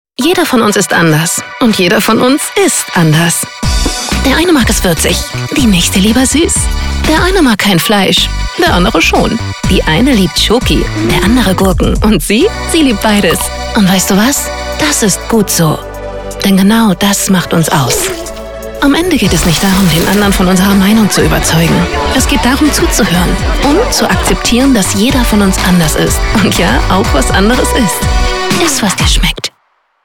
hell, fein, zart, sehr variabel, markant, plakativ
Jung (18-30)
Norddeutsch
Eigene Sprecherkabine
Commercial (Werbung)